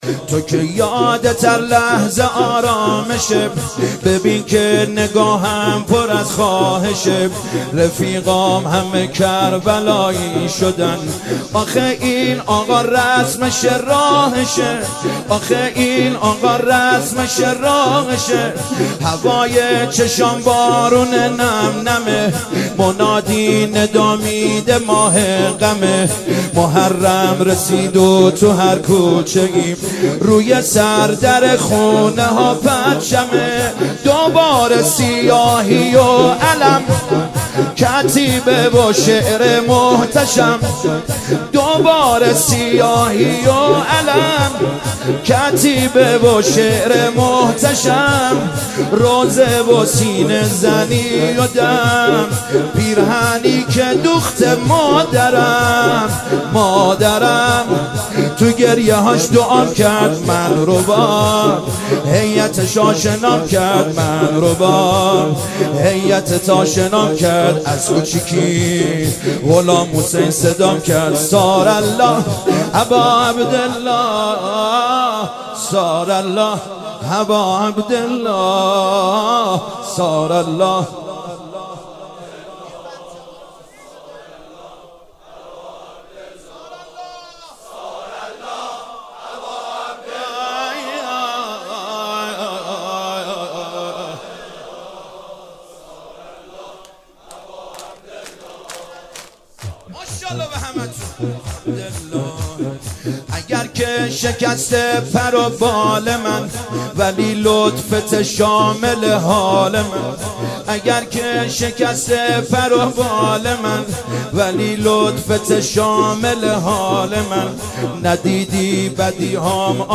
مداحی جدید
شب دوم محرم97 هیات کربلا رفسنجان
شور